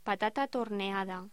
Locución: Patata torneada
voz